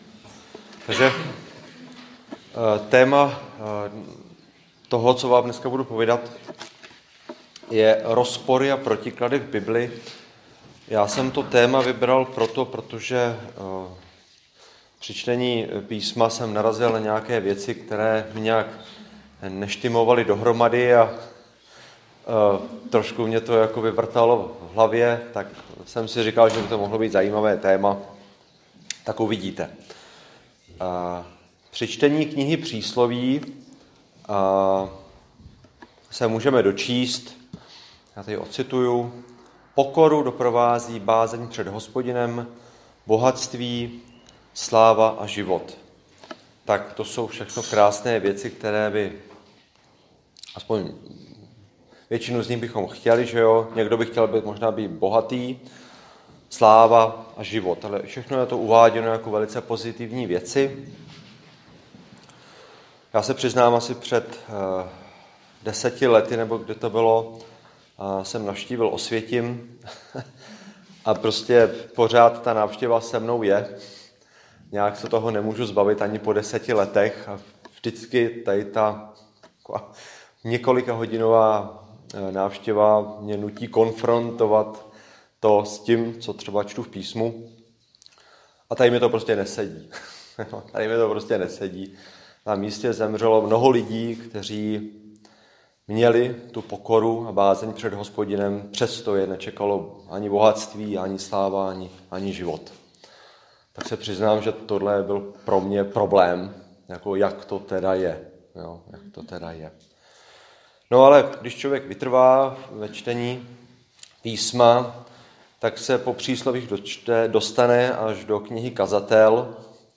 Křesťanské společenství Jičín - Kázání 13.6.2021